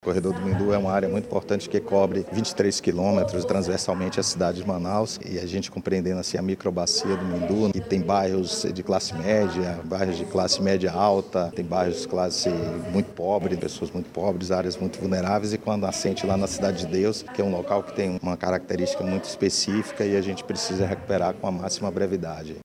A previsão é que em dezembro de 2023 o resultado do diagnóstico seja divulgado, destaca o vice-presidente do Conselho de Gestão Estratégica do município, Alessandro Moreira.